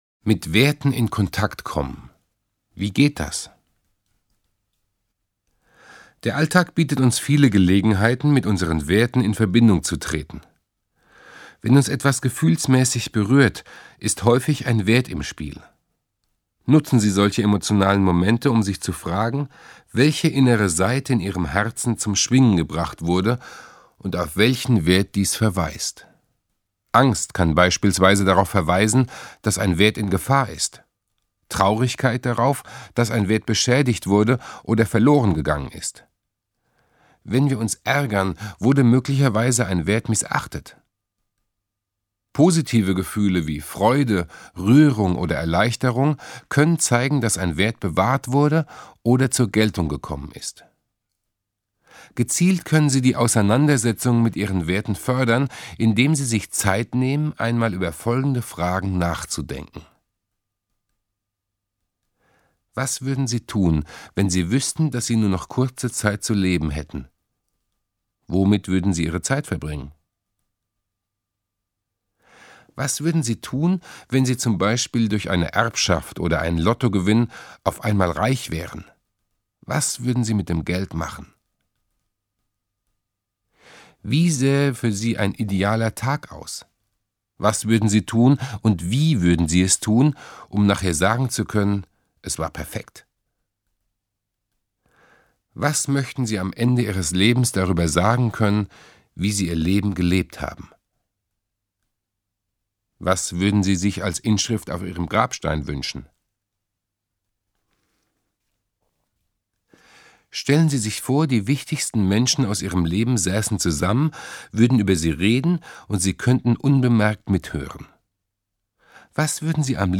Lebenshilfe zum Hören